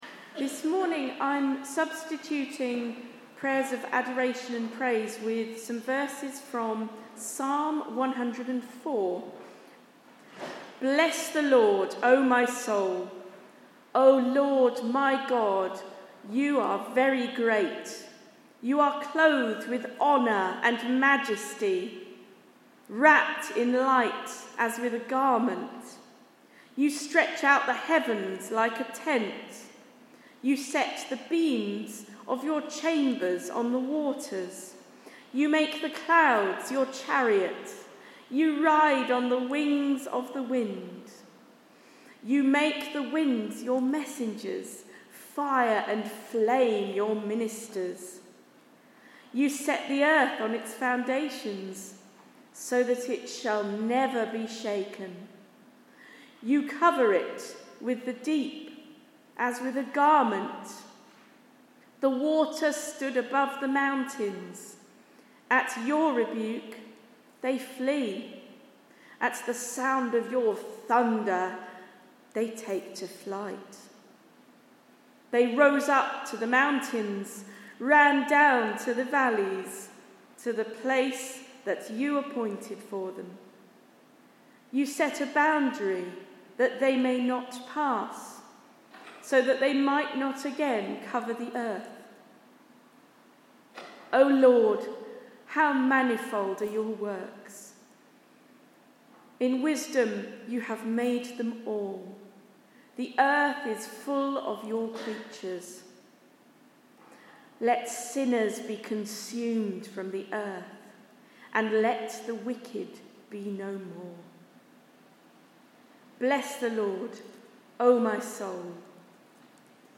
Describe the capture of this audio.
My reading of verses of praise from Psalm 104 followed by my prayers of confession near the start of a service on 20 October 2024